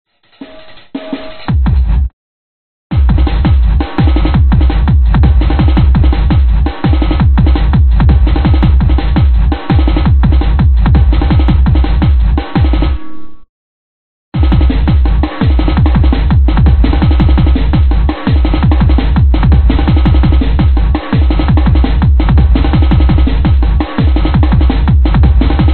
描述：强有力的鼓与贝斯的断奏
Tag: 低音 休息 舞蹈 丛林